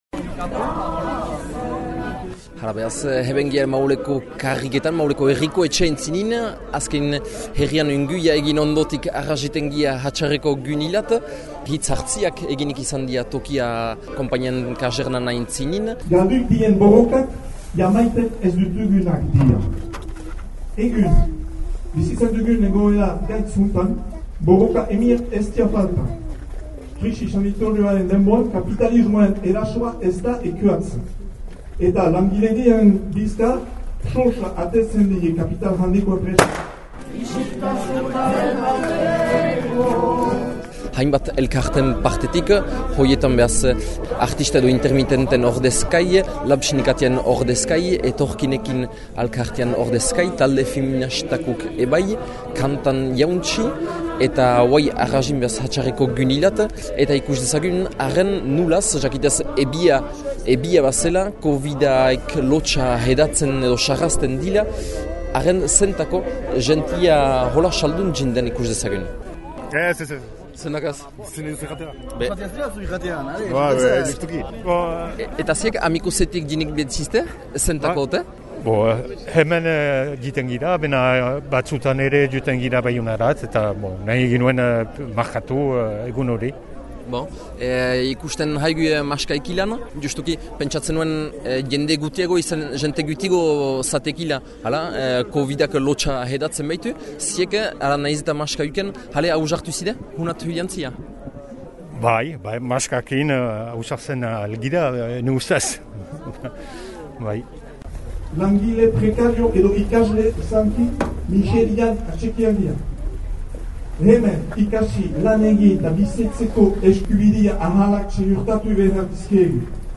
Hitz hartze ezbardinetaz gain karrika ünürü alageratü bat egin da.